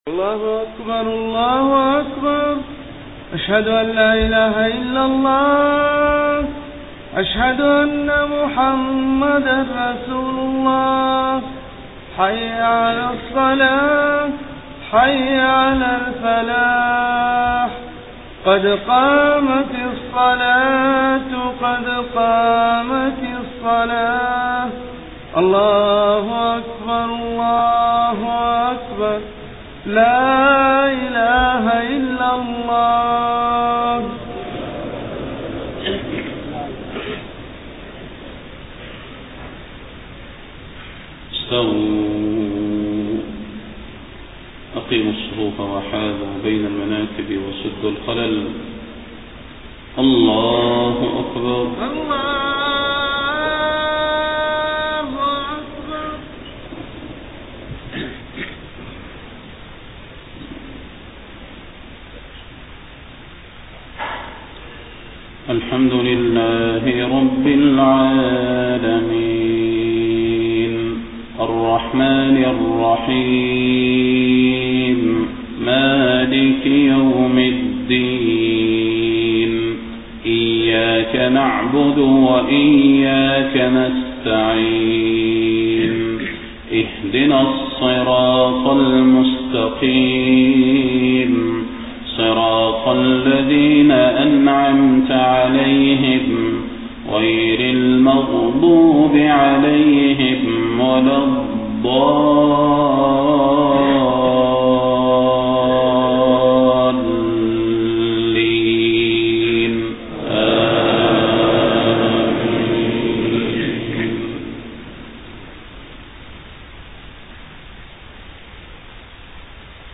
صلاة الفجر 5 ربيع الأول 1431هـ خواتيم سورة ق 16-45 > 1431 🕌 > الفروض - تلاوات الحرمين